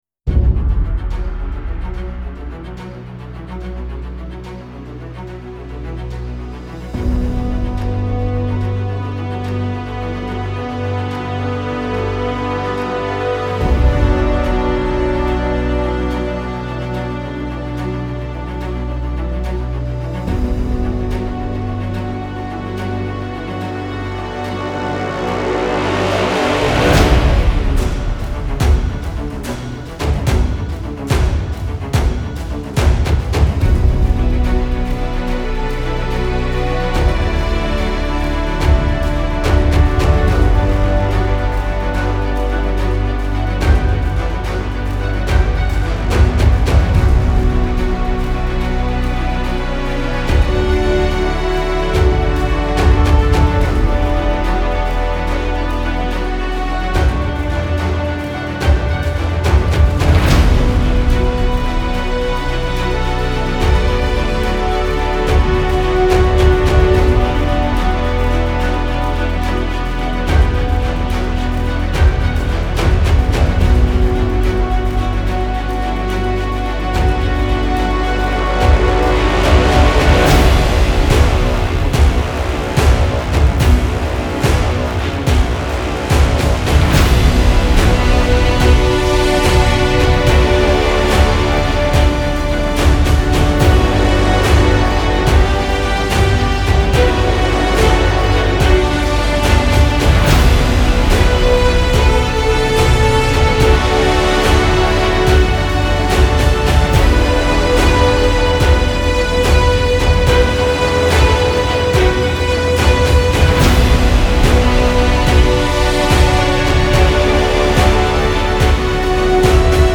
اپیک , الهام‌بخش , حماسی